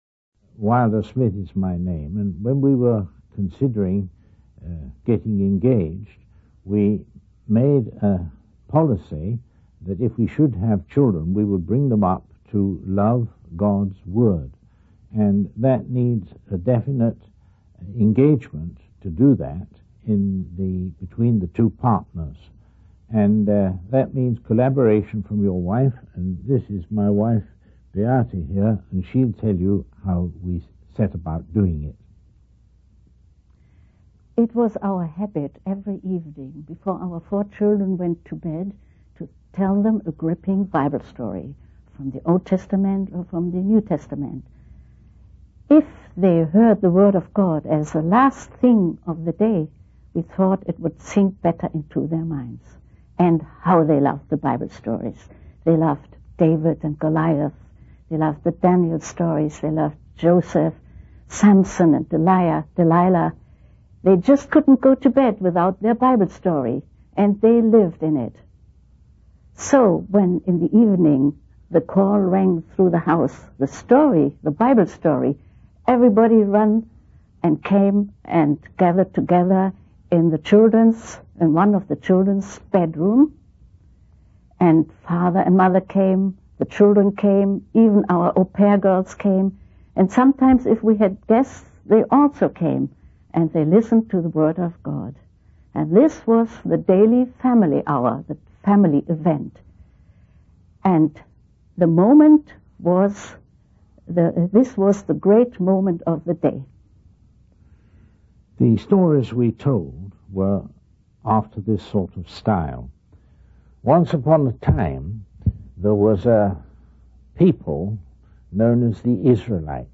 In this sermon, the speaker discusses the importance of teaching children about the word of God.